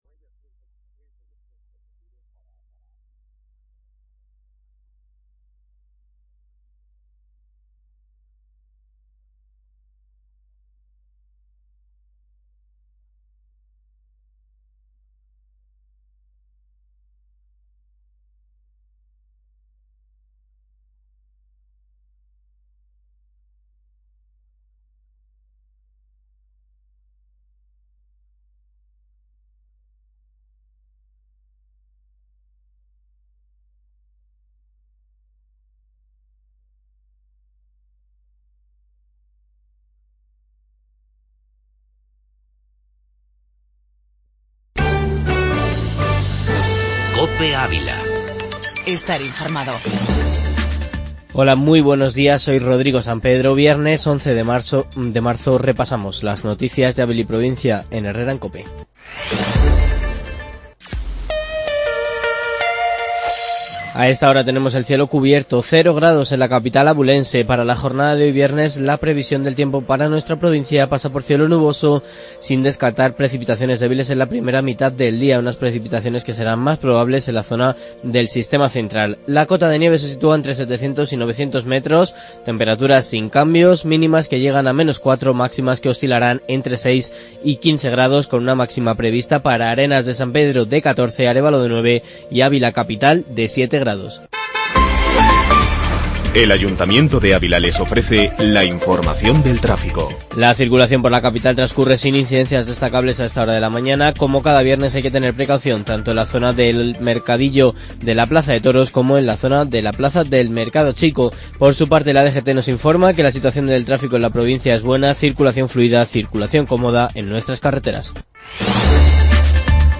Informativo matinal en 'Herrera en Cope'.